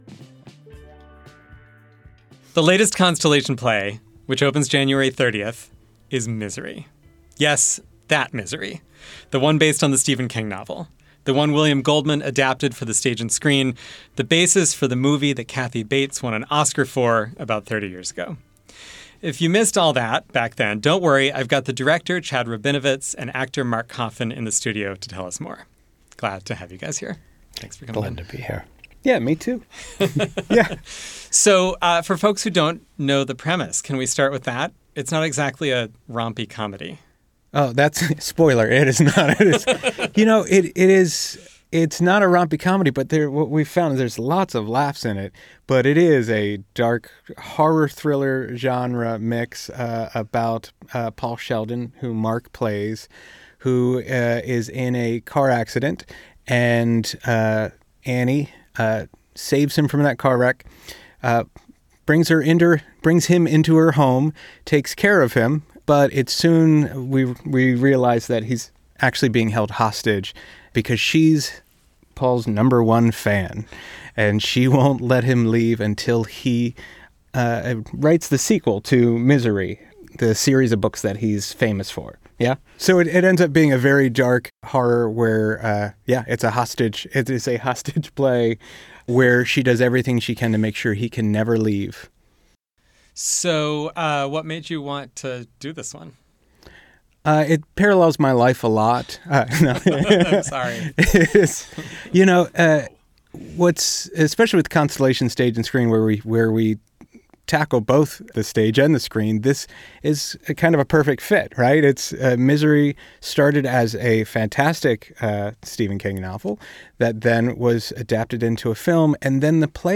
Arts and culture news, events, interviews, and features from around southern and central Indiana.